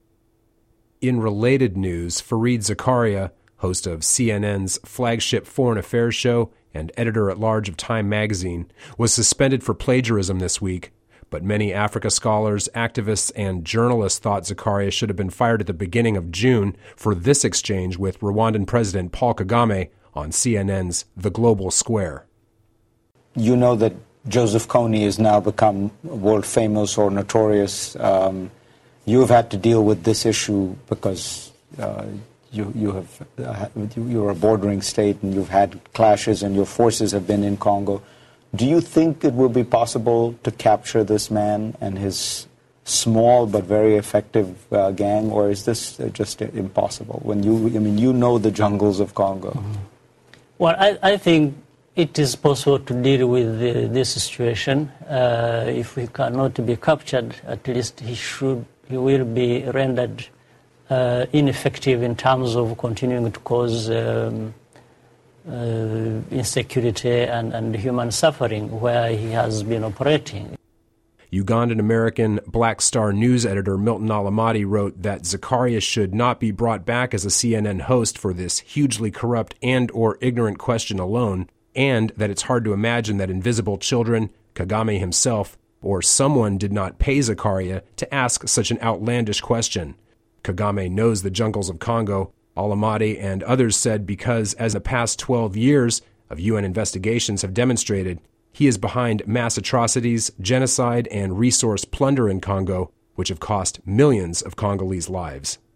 CNN Global Square host Fareed Zakaria asked Rwandan President Paul Kagame whether he thought his soldiers might be able to catch East African warlord Joseph Kony to stop human suffering in the Democratic Republic of Congo, though the last 12 years of UN human rights investigations document war crimes, crimes against humanity, and even genocide committed by Kagame's army and proxy militias there.